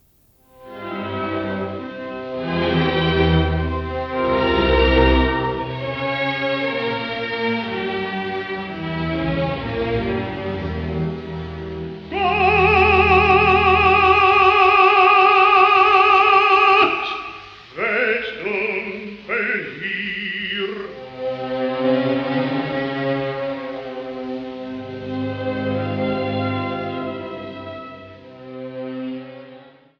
Opernszenen
Gewandhausorchester Leipzig, Gustav Brecher, Paul Schmitz
Der zweite Teil der CD-Serie enthält auf zwei CDs insgesamt 15 Ausschnitte von acht Opern in Aufnahmen von 1929 bis 1945.